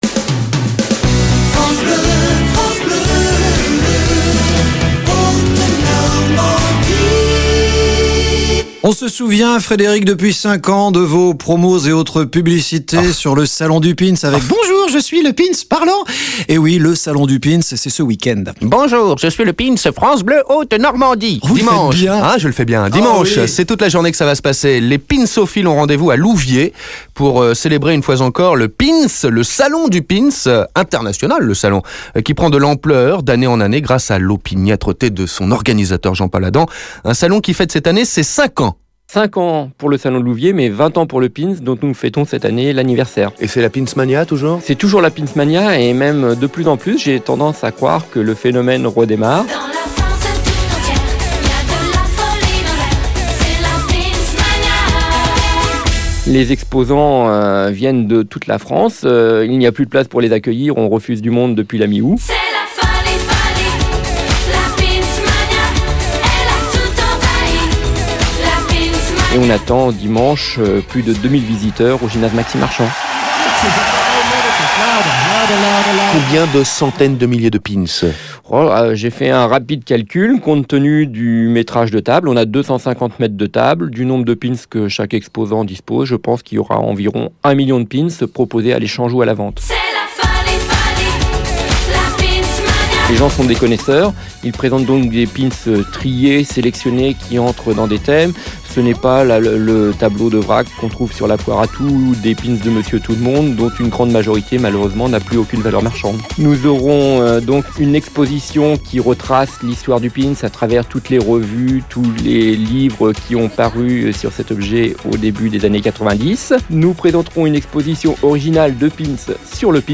France Bleu Haute-Normandie - Annonce du salon (2 octobre 2007)